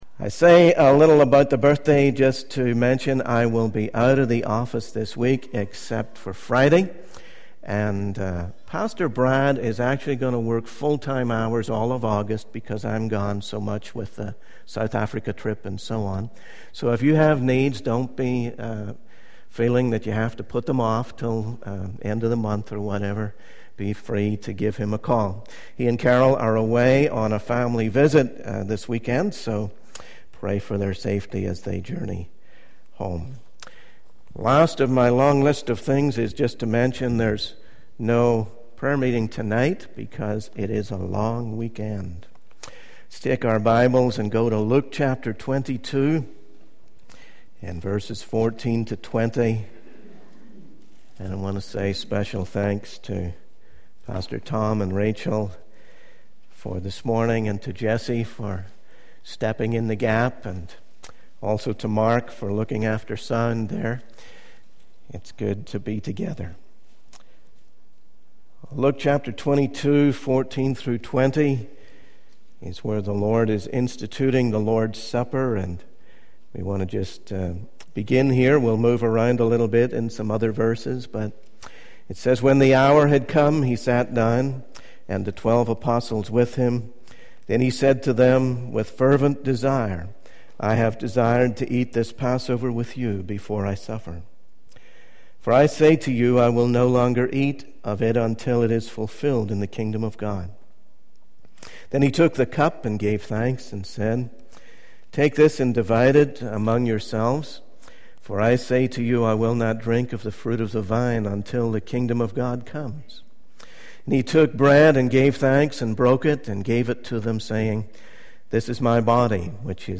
In this sermon, the speaker emphasizes the importance of remembering Jesus and his sacrifice. They encourage the audience to come to the communion table and die to themselves, choosing to live the Christ life instead. The speaker also discusses the fulfillment of the law through Jesus and how believing in him brings righteousness into our lives.